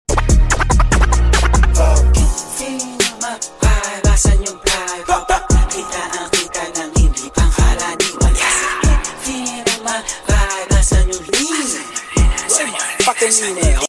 Big tires kapuge . . sound effects free download